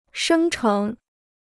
生成 (shēng chéng): to generate; to produce.